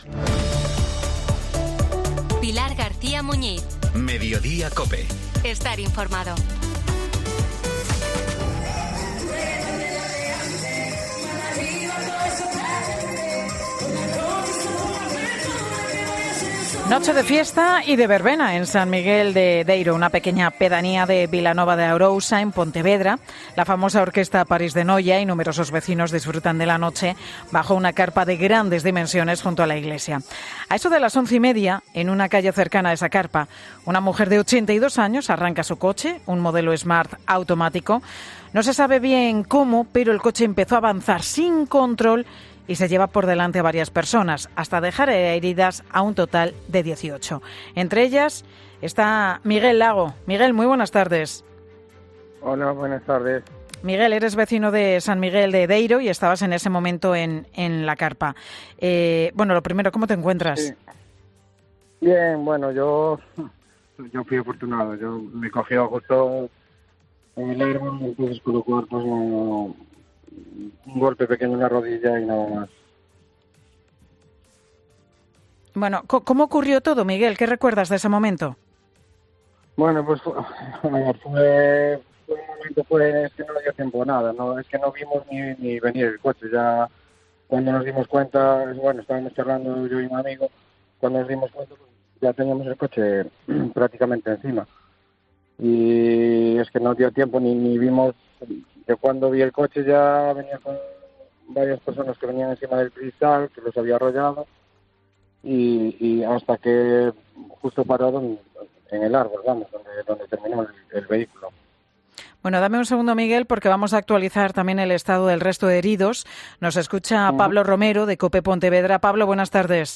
Una de las víctimas del atropello de Vilanova de Arousa en 'Mediodía COPE'
"El coche fue a parar a un árbol, el crío estaba atrapado, tuvimos muchas dificultades para sacar al niño, no podíamos levantar el coche", describe con intensidad en la voz.